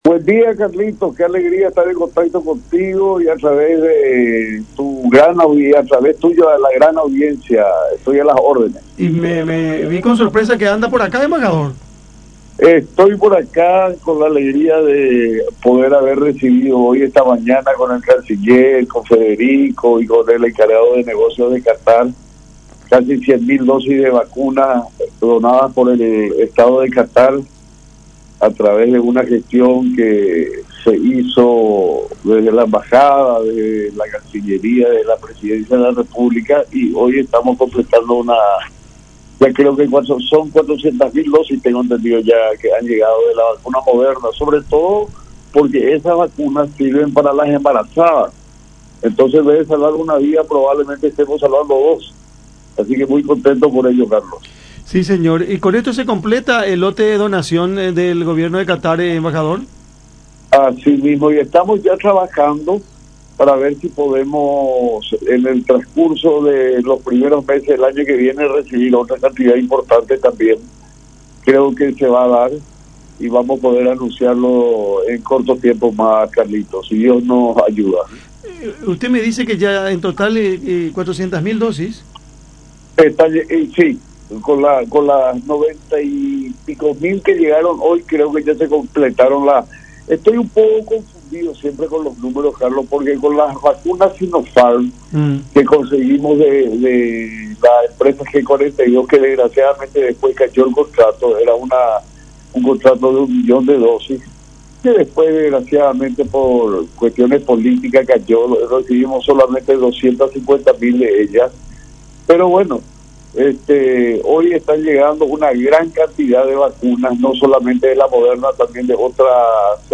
“Son dosis que son donadas por el Gobierno de Qatar y que son especialmente para las embarazadas. Las dosis de las vacunas Moderna, pueden salvar la vida no solo de una persona, sino de dos, por eso es muy importante que las reciban las mujeres embarazadas”, destacó Ángel Barchini, embajador paraguayo en Catar, en charla con Cada Mañana por La Unión, resaltando que también se aplicarán como segundas dosis a quienes las tienen pendientes.